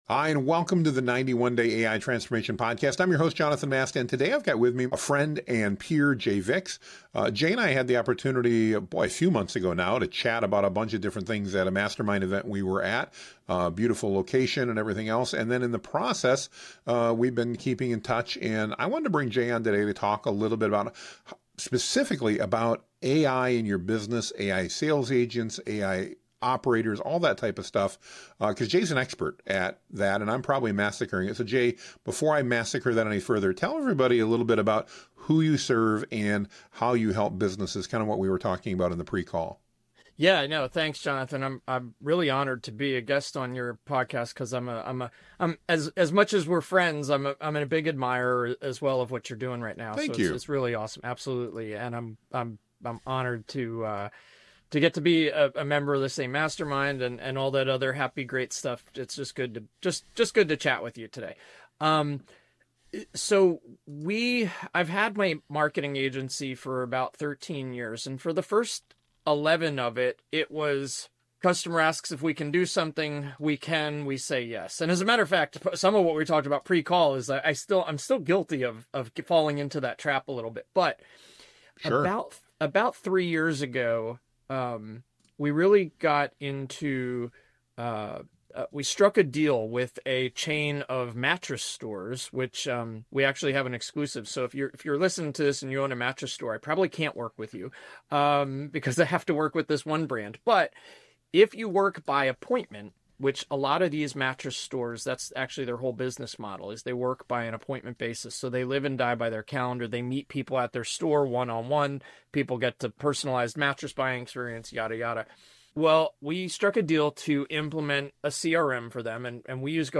A practical, honest discussion for anyone considering sales agents and wanting to avoid the biggest points of failure while setting themselves up for long-term wins.